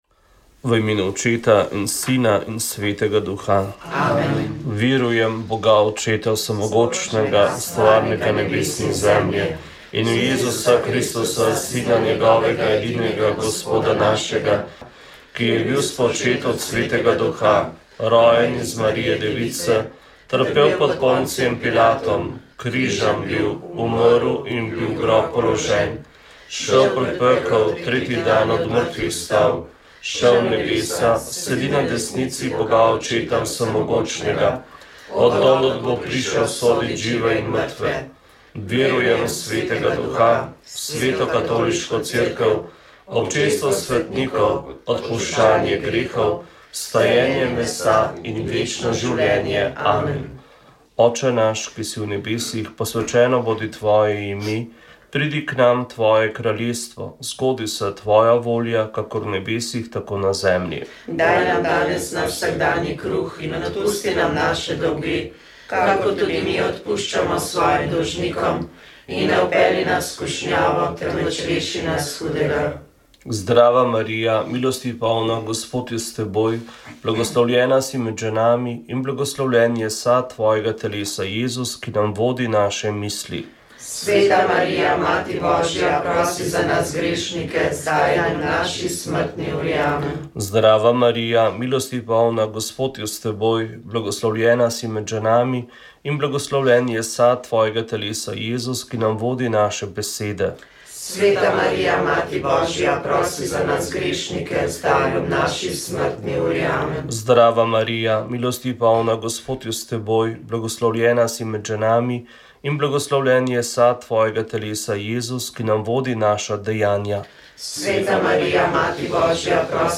Filipinski kardinal Luis Antonio Tagle je nedavno postal vodja pomembne vatikanske kongregacije za evangelizacijo narodov. Zato smo v oddaji ponovili njegov nagovor, s katerim je letos odprl radijski misijon na Radiu Ognjišče.